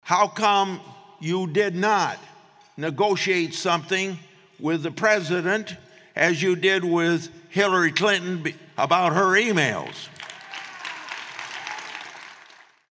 Grassley asked during his speech to the GOP crowd, who responded with applause and cheers.